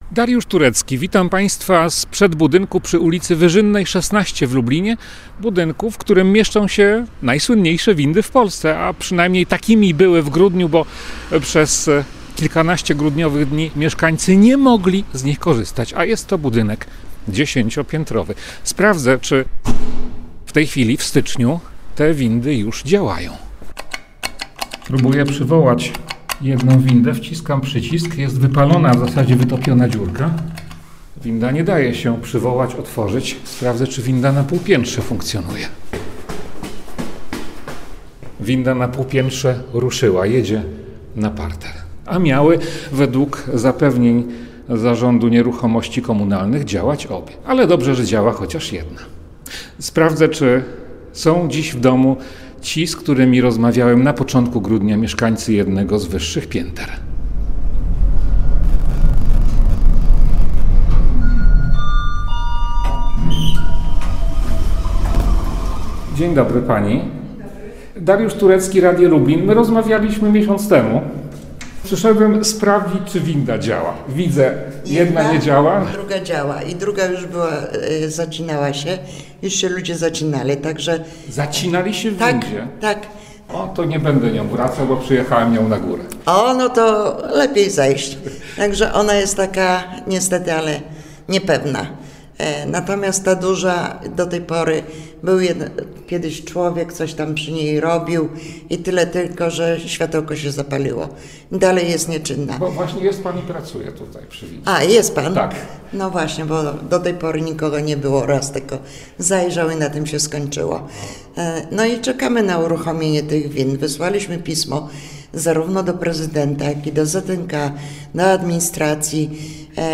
Sprawdzam to osobiście, rozmawiam z mieszkanką budynku, informacji zasięgam także w Urzędzie Dozoru Technicznego.